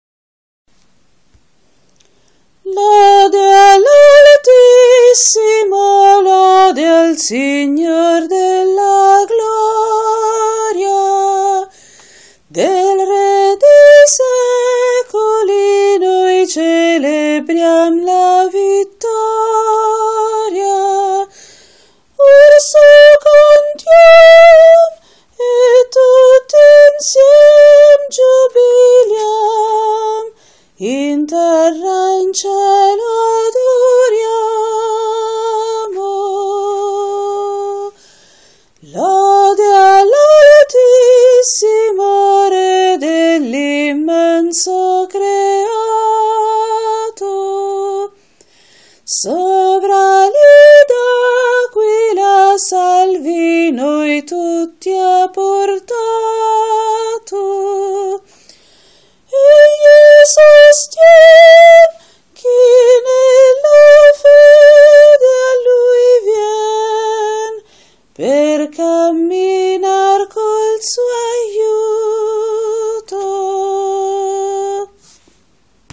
Parti Cantate
In questa sezione sono raccolte le parti cantate, delle diverse sezioni, in riferimento ad alcuni dei brani del Repertorio Condiviso.